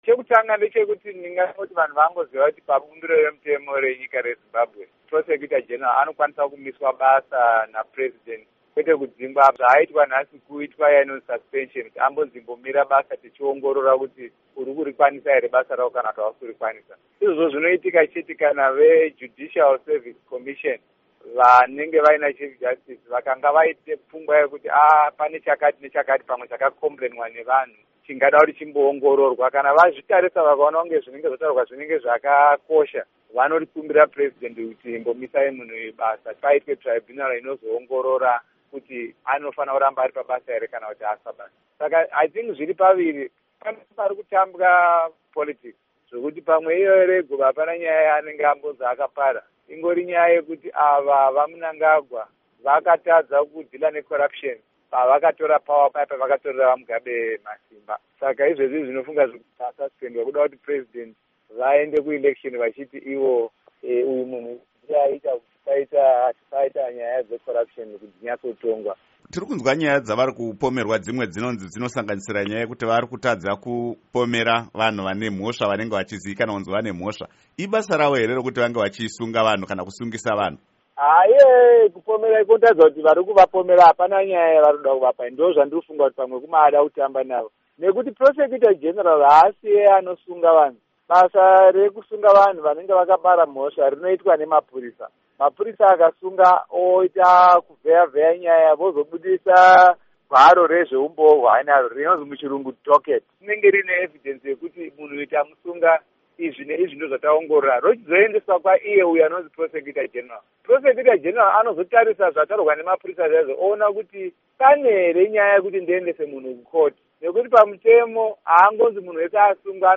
Hurukuro naMuzvinafundo Lovemore Madhuku